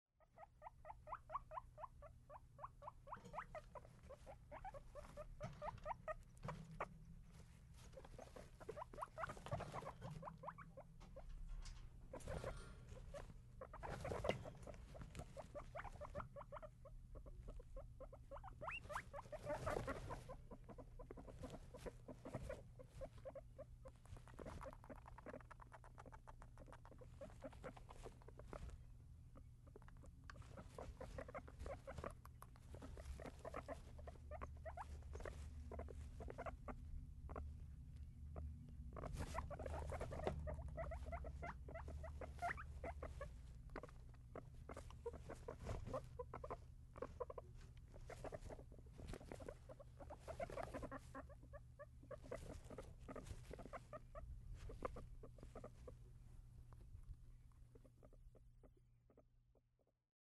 Звуки морской свинки в клетке